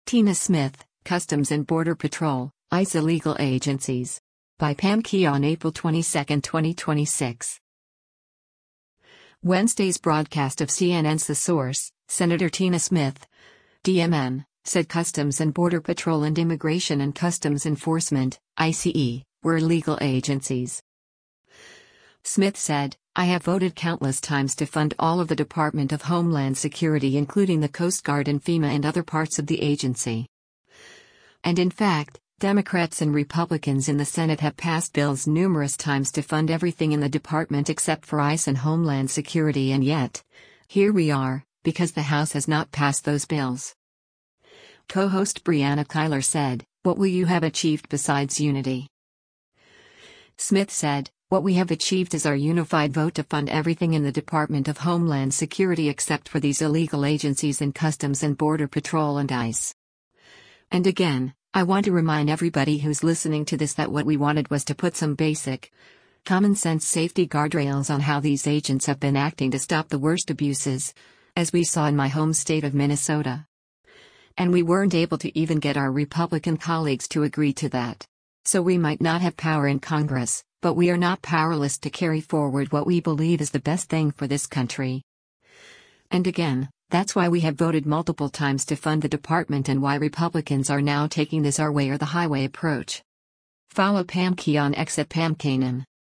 Wednesday’s broadcast of CNN’s “The Source,” Sen. Tina Smith (D-MN) said Customs and Border Patrol and Immigration and Customs Enforcement (ICE) were “illegal agencies.”